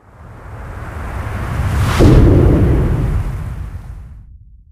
gravi_blowout5.ogg